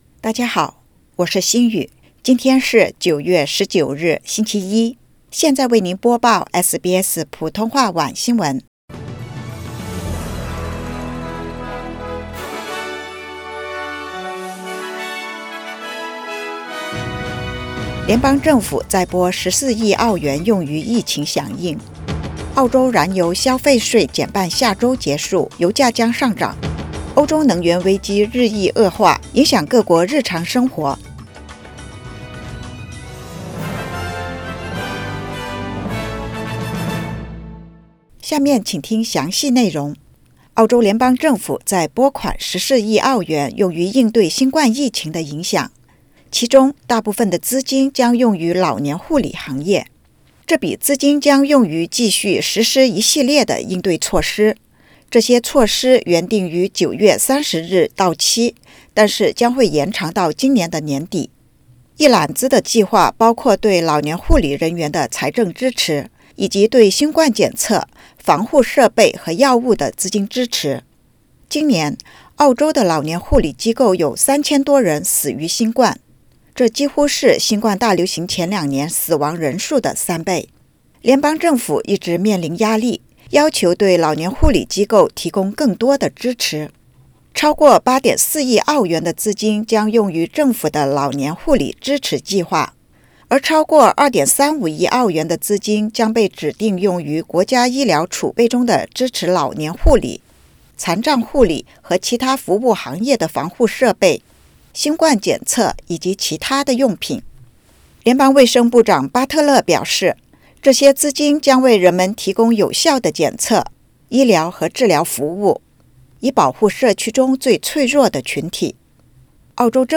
SBS Mandarin evening news Source: Getty / Getty Images